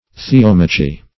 Search Result for " theomachy" : The Collaborative International Dictionary of English v.0.48: Theomachy \The*om"a*chy\, n. [Gr.